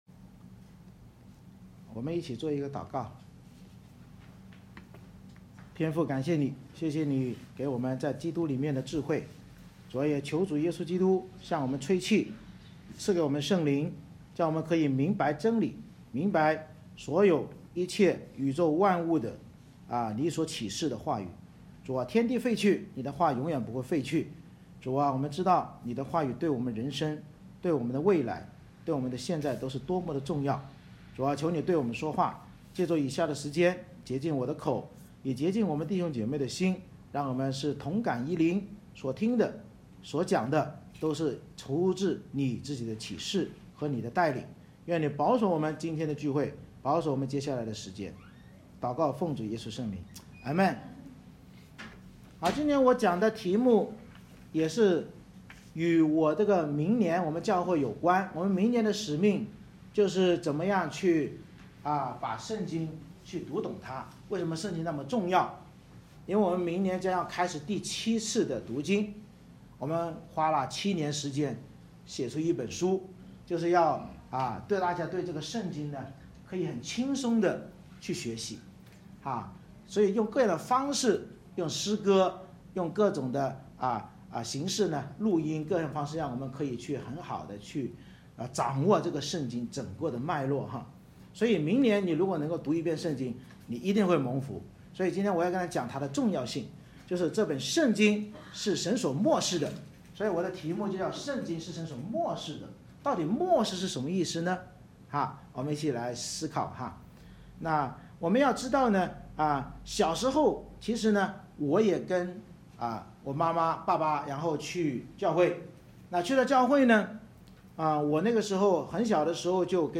提摩太后书3:14-17 Service Type: 主日崇拜 通过三方面即圣经有得救的智慧、圣经是神所默示的、圣经有行善的标准，教导我们要信靠耶稣基督并坚守圣经的绝对权威性、准确性和可靠性，才能对世上一切作出正确判断和选择，凡事得益。